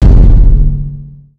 sfx_boss.wav